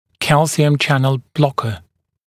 [‘kælsɪəm ‘ʧænl ‘blɔkə][‘кэлсиэм ‘чэнл ‘блокэ]блокатор кальциевых каналов